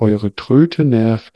sample02-wavegrad.wav